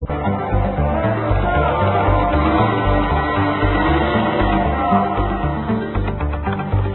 3 campionamenti di canti di nativi americani